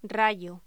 Locución: Rayo
Sonidos: Voz humana